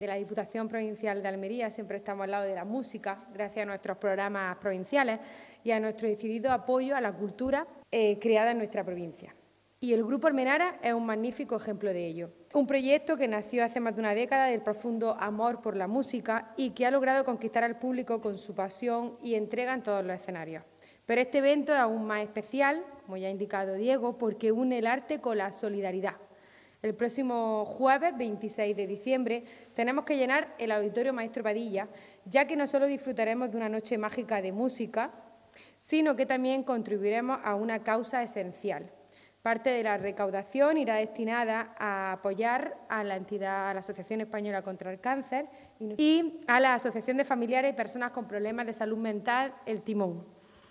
23-12_concierto_navidad_diputada.mp3